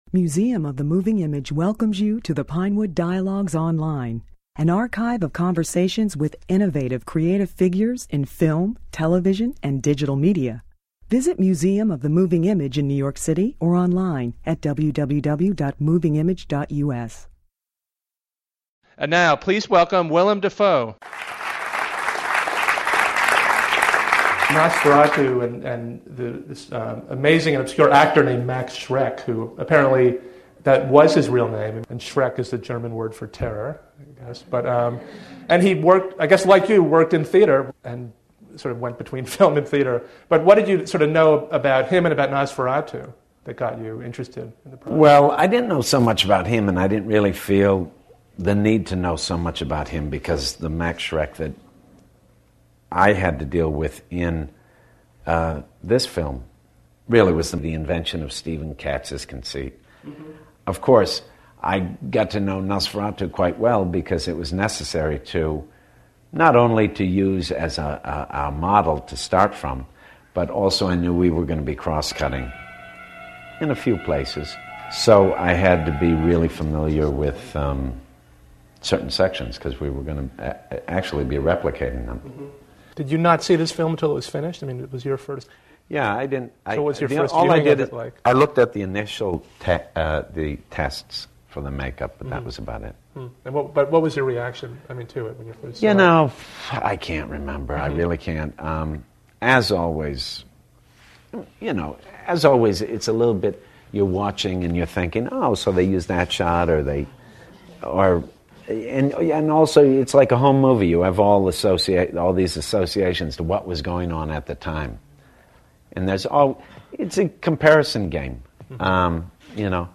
At the time of this interview, Dafoe was winning acclaim for his performance as the notorious film star Max Schreck in Shadow of the Vampire , an inventive behind-the-scenes movie about the making of Nosferatu . Dafoe talked about the process of film acting, including the startling physical transformation he had undergone for this role.